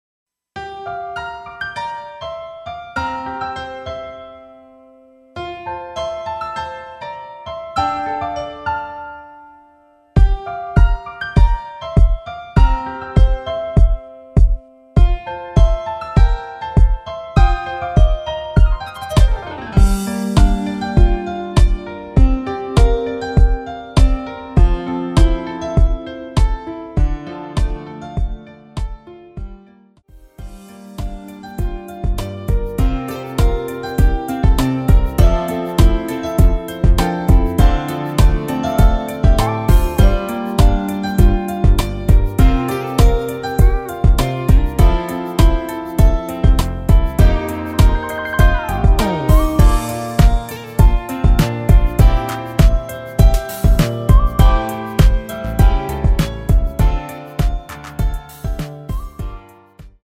MR입니다.
앞부분30초, 뒷부분30초씩 편집해서 올려 드리고 있습니다.
중간에 음이 끈어지고 다시 나오는 이유는
위처럼 미리듣기를 만들어서 그렇습니다.